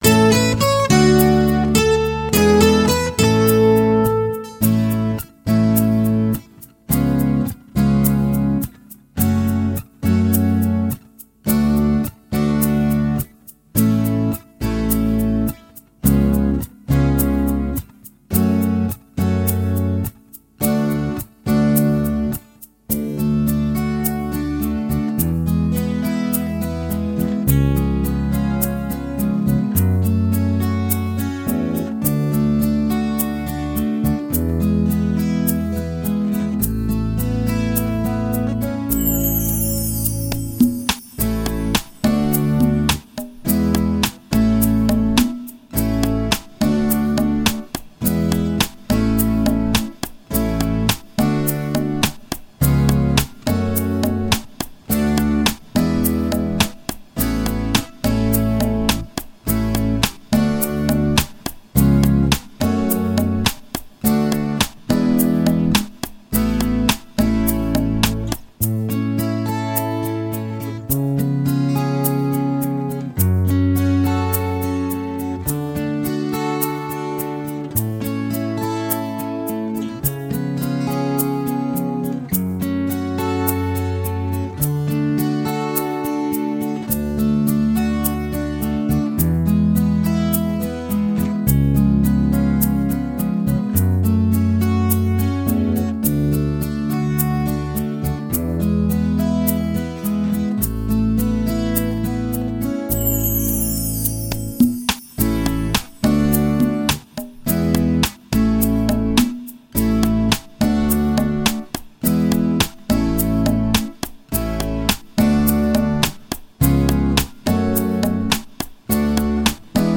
Здесь вы можете бесплатно скачать минусовку